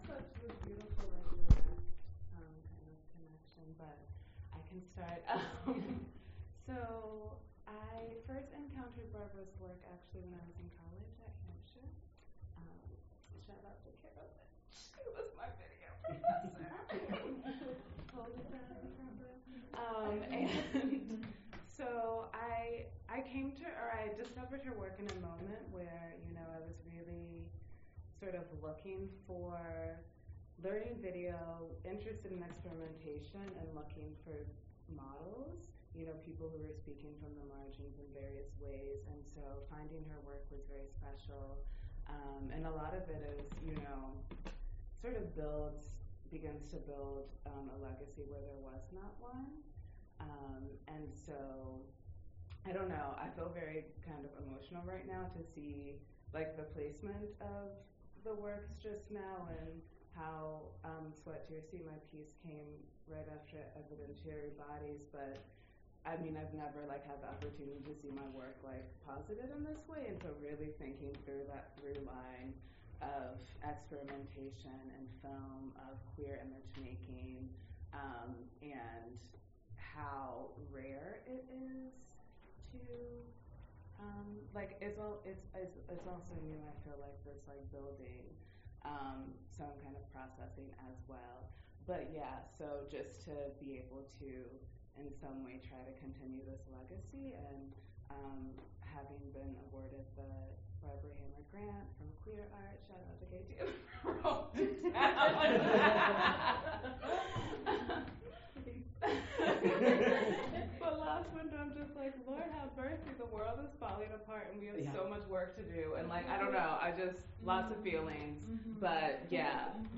An panel with filmmakers